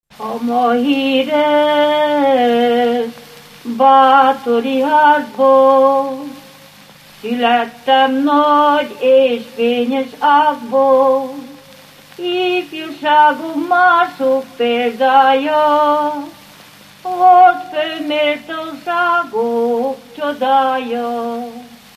Erdély - Udvarhely vm. - Szentegyházasfalu
Műfaj: Históriás ének
Stílus: 8. Újszerű kisambitusú dallamok
Szótagszám: 9.9.9.9
Kadencia: 2 (2) 3 1